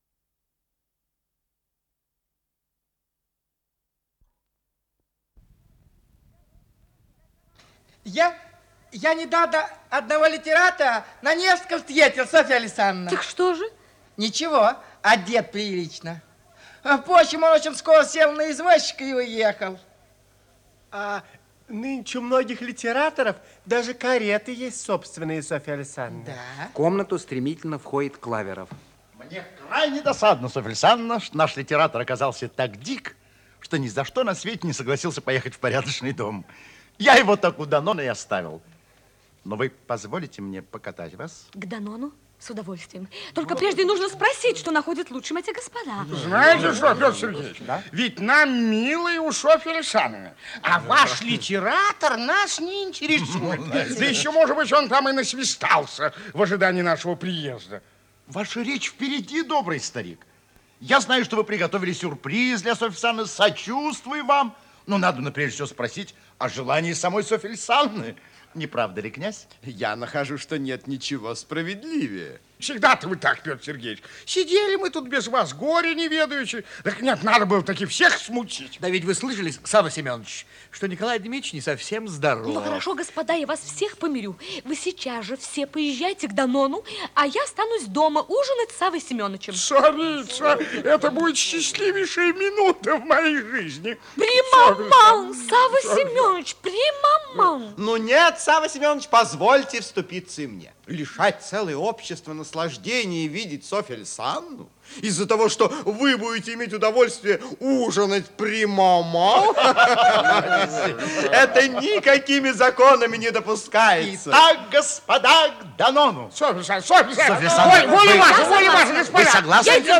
Исполнитель: Артисты государственного ленинградского нового театра